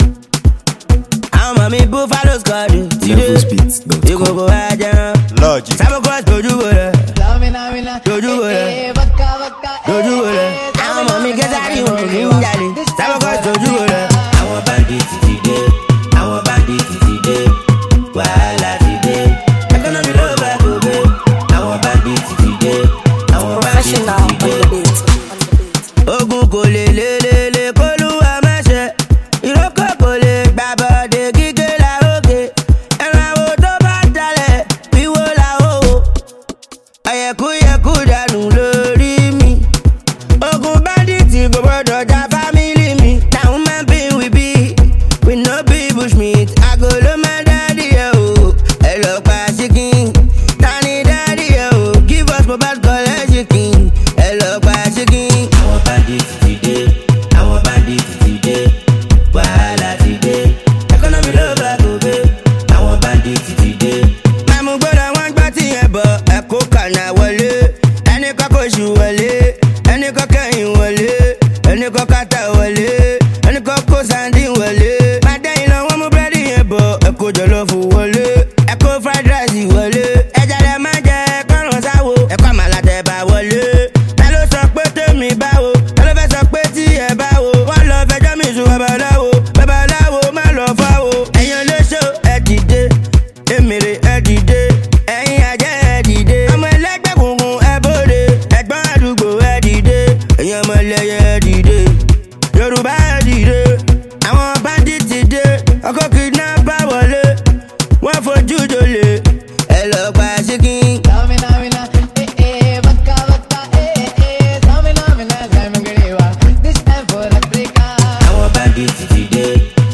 blending vibrant rhythms with his unique vocal delivery.